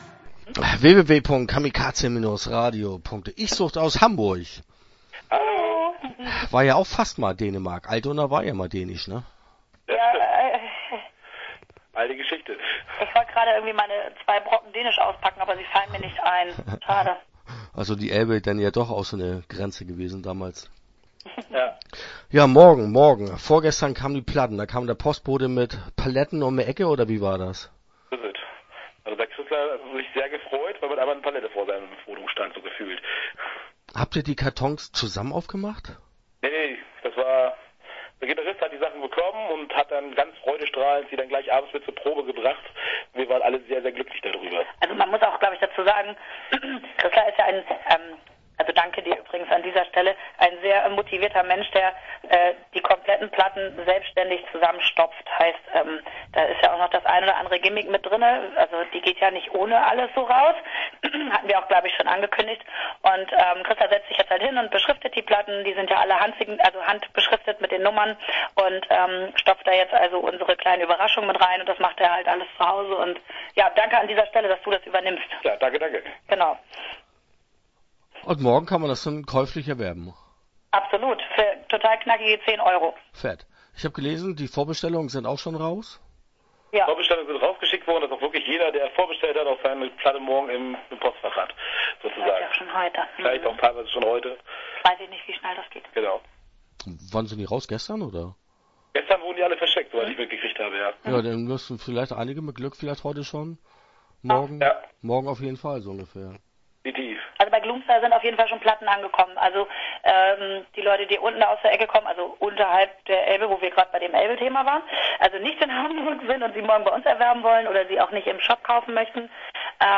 ICHSUCHT - Interview Teil 1 (5:17)